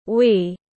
Chúng tôi tiếng anh gọi là we, phiên âm tiếng anh đọc là /wiː/.
We /wiː/